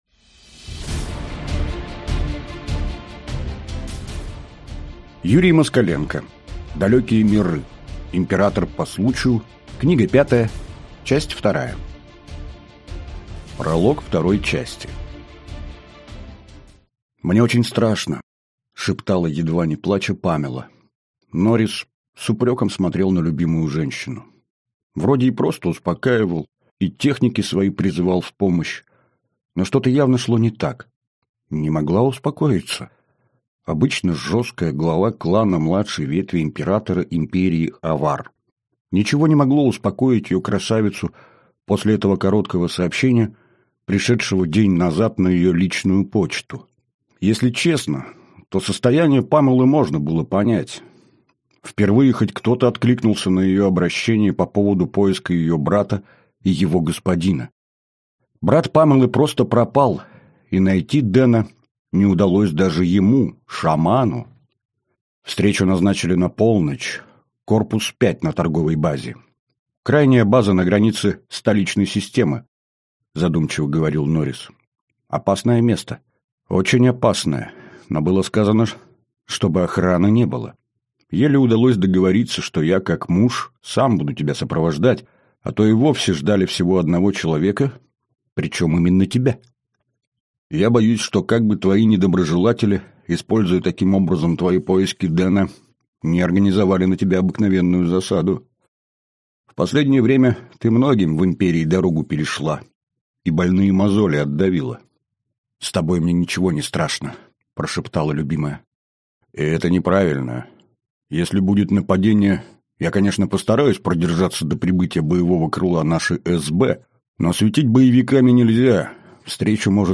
Аудиокнига Далекие миры. Император по случаю. Книга пятая. Часть вторая | Библиотека аудиокниг